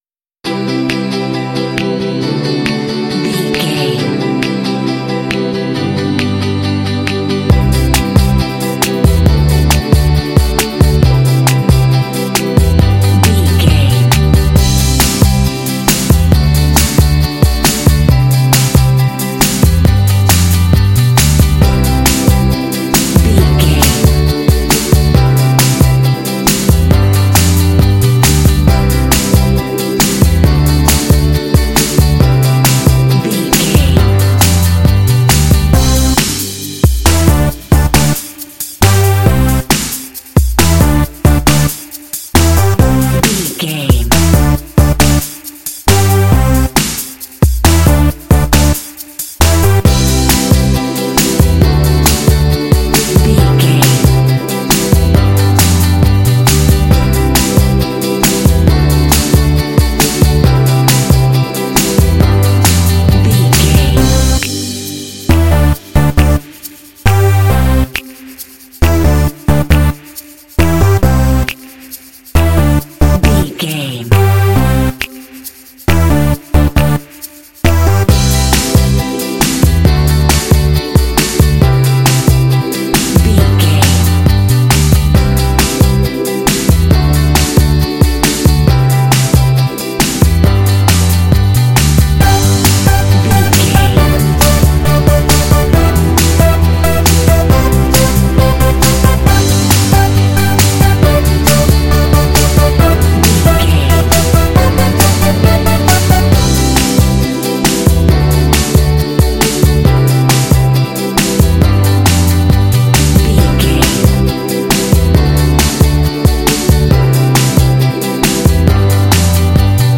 Uplifting
Ionian/Major
energetic
fun
playful
cheerful/happy
synthesiser
drums
bass guitar
acoustic guitar
contemporary underscore